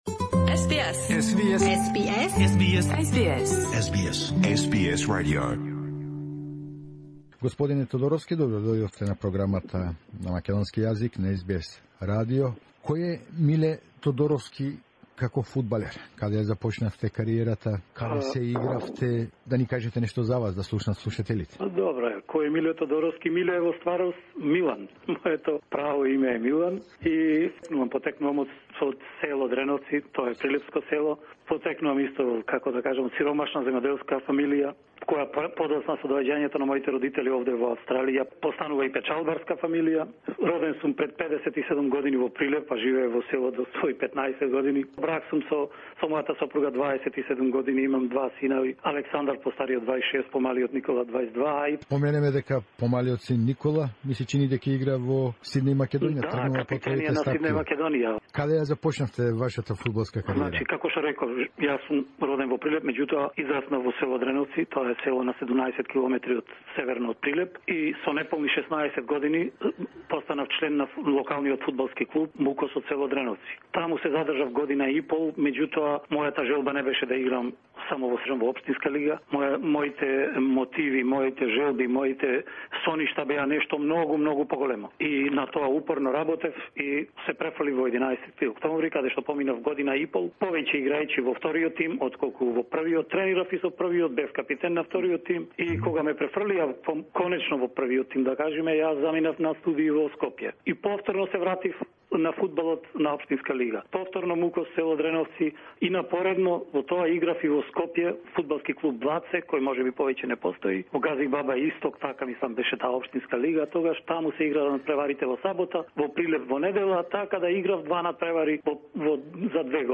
Во разговор за СБС Радио